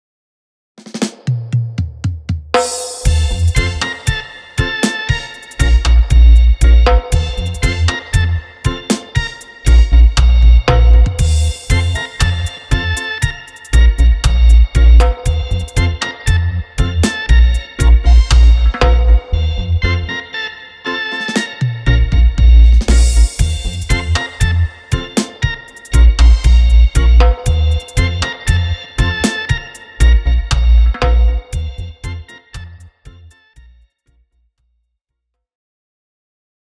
Créez des grooves de batterie authentiques et puissants pour tous les genres qui exigent des rythmes profonds et résonnants.